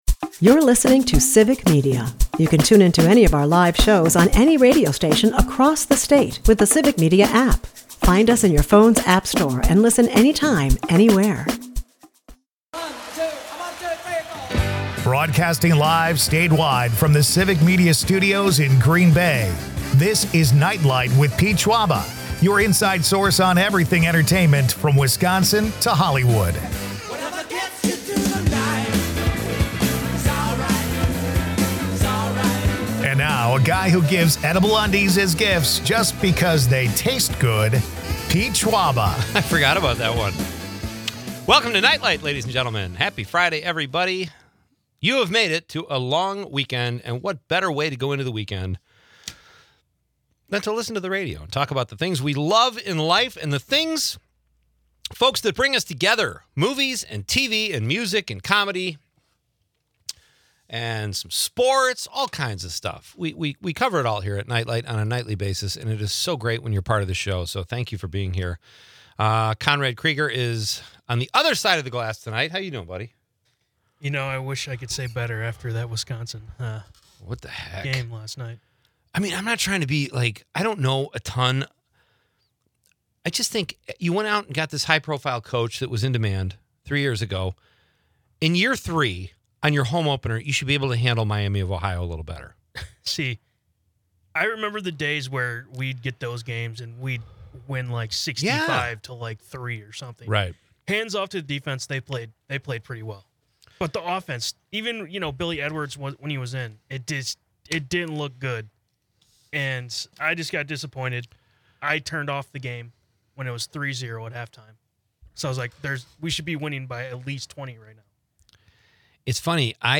The episode is peppered with quirky anecdotes, a keyword contest for a resort stay, and the nostalgic charm of radio's golden days.